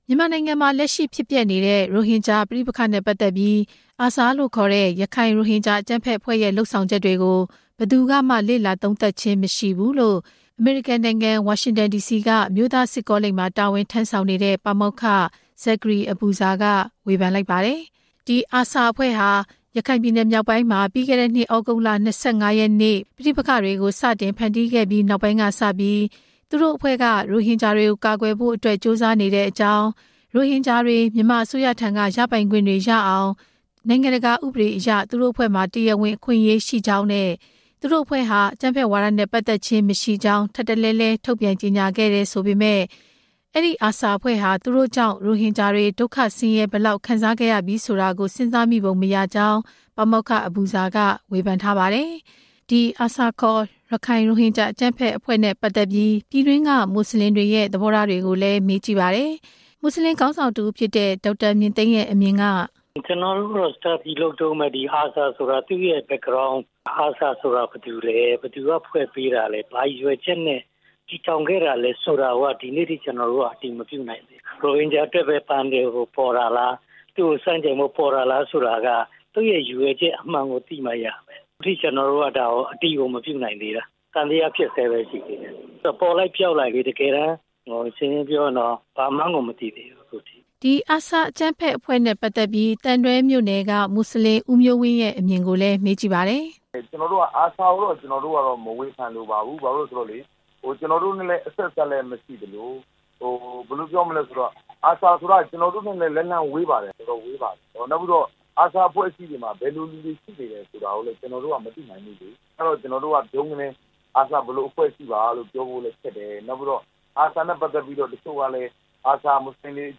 ပြည်တွင်းက မွတ်စလင်တချို့ကို မေးမြန်းပြီး